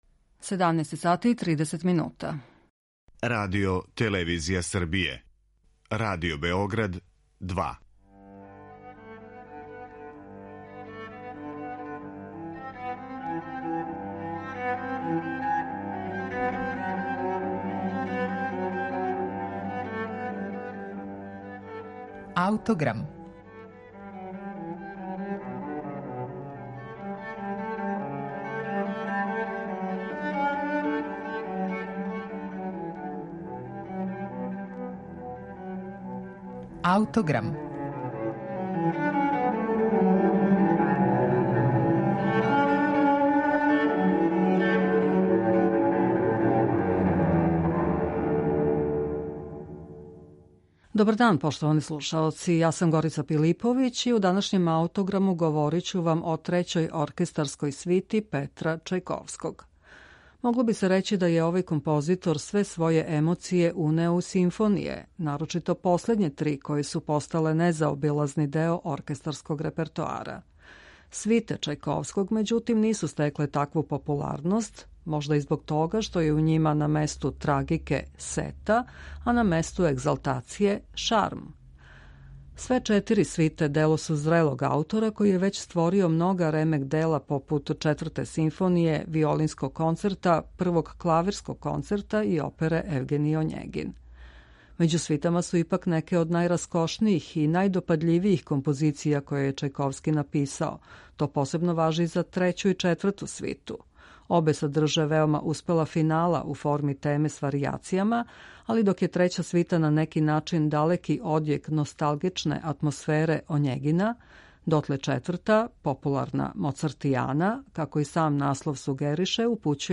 Трећа оркестарска свита Чајковског